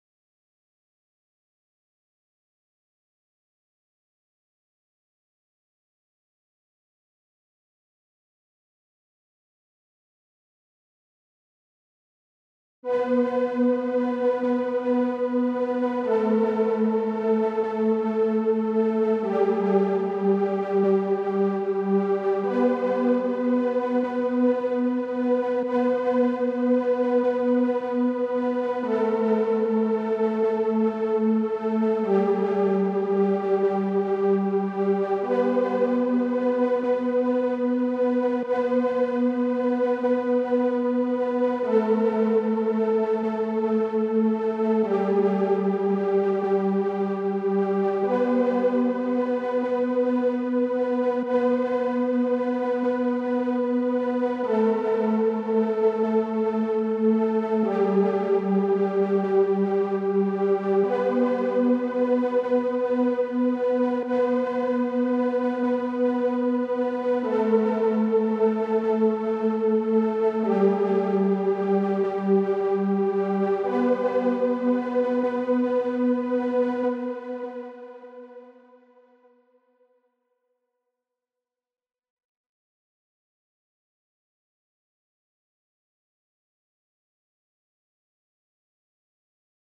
🔹 50 Custom Serum Presets crafted for wave pop, ambient electronica, and deep emotional beats.
These presets feel like water: fluid, lush, and endlessly immersive.
Dreamy Pads & Evolving Textures – Perfect for ambient intros or bridges